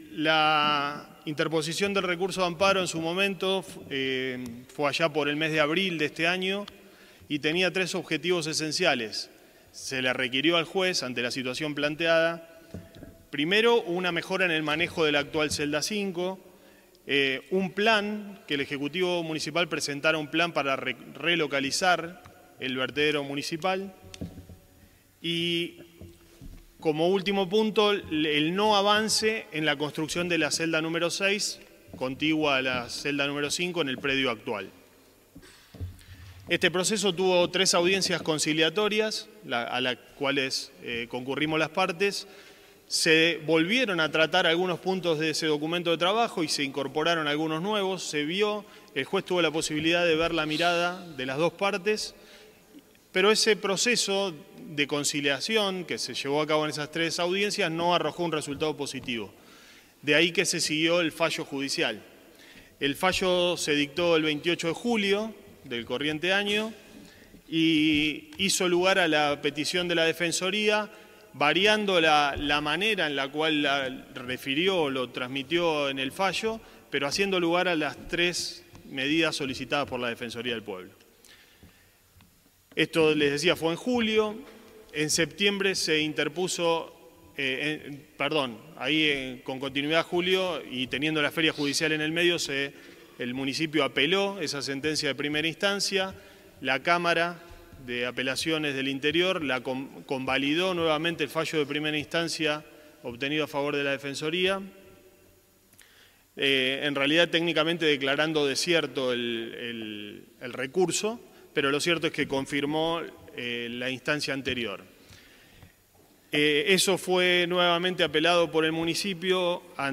Estas son algunas de las voces que estuvieron presentes en la audiencia.